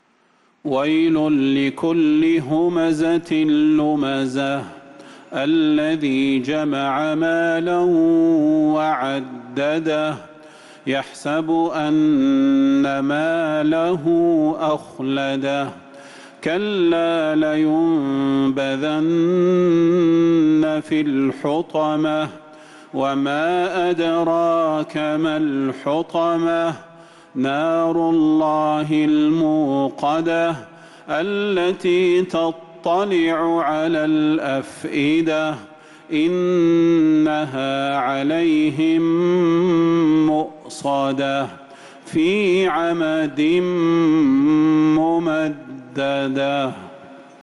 سورة الهمزة | صفر 1447هـ > السور المكتملة للشيخ صلاح البدير من الحرم النبوي 🕌 > السور المكتملة 🕌 > المزيد - تلاوات الحرمين